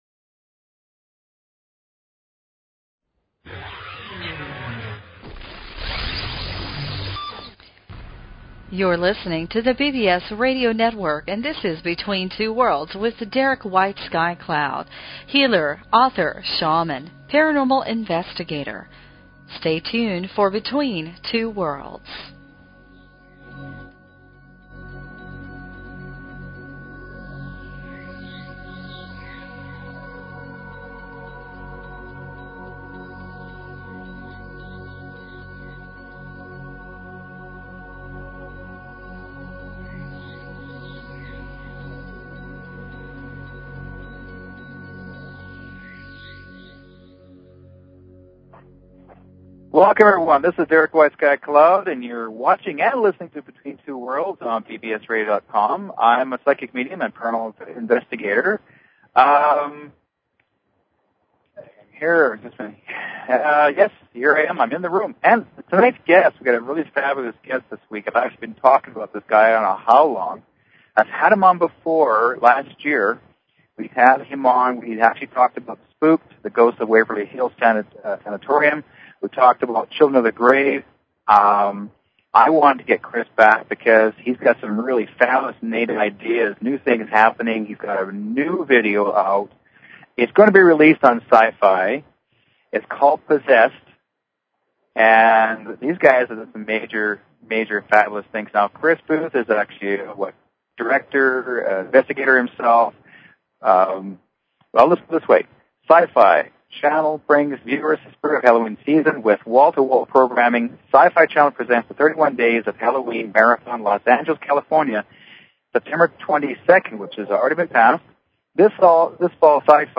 Talk Show Episode, Audio Podcast, Between_Two_Worlds and Courtesy of BBS Radio on , show guests , about , categorized as